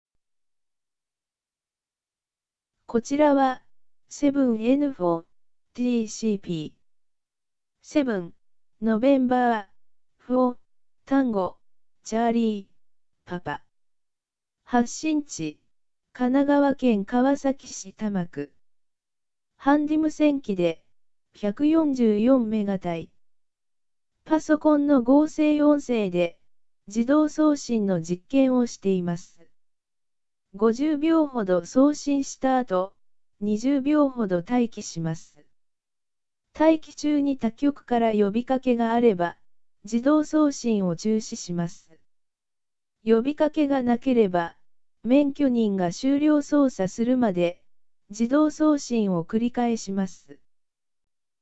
自動送信する音声メッセージはパソコンで簡単に生成できます。
これは現在MP3プレーヤーにセットしている音声メッセージの実例です。